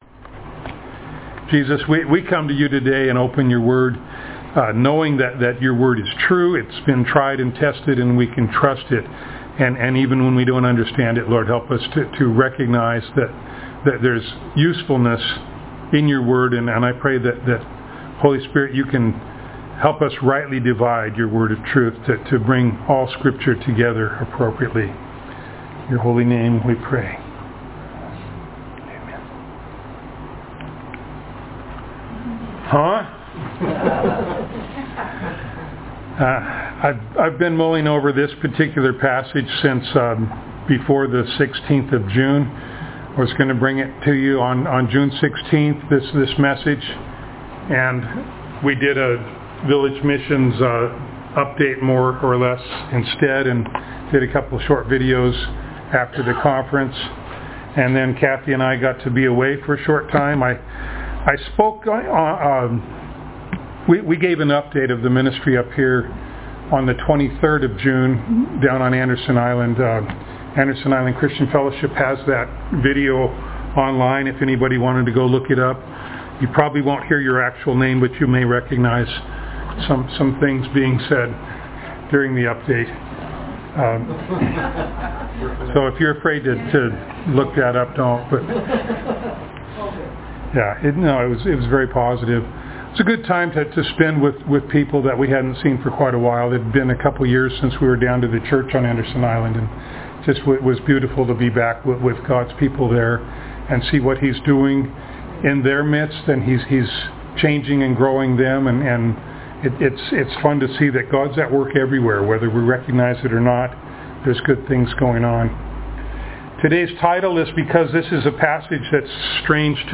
The recording device malfunctioned part way through the recording. We only have the first 25 minutes of a 35 minute sermon.
1 Corinthians 11:1-16 Service Type: Sunday Morning The recording device malfunctioned part way through the recording.